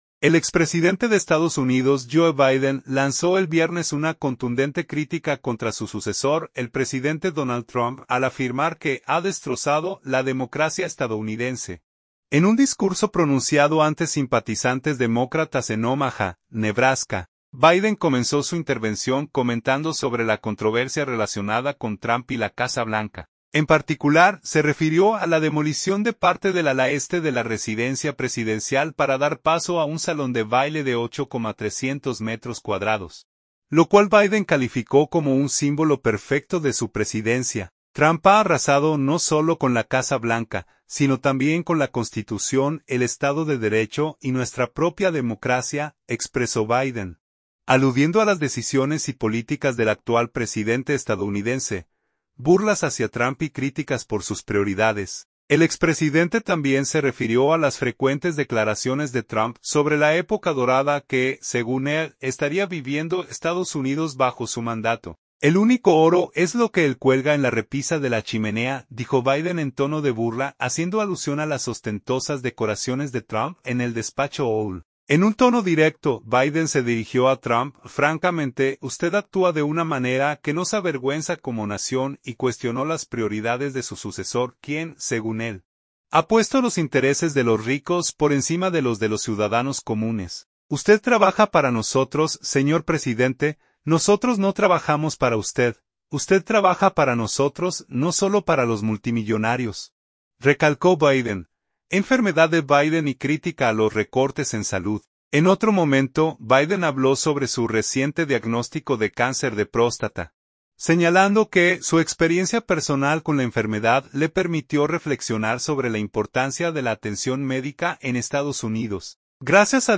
El expresidente de Estados Unidos Joe Biden lanzó el viernes una contundente crítica contra su sucesor, el presidente Donald Trump, al afirmar que ha “destrozado” la democracia estadounidense, en un discurso pronunciado ante simpatizantes demócratas en Omaha, Nebraska.
En un tono directo, Biden se dirigió a Trump: “Francamente, usted actúa de una manera que nos avergüenza como nación”, y cuestionó las prioridades de su sucesor, quien, según él, ha puesto los intereses de los ricos por encima de los de los ciudadanos comunes.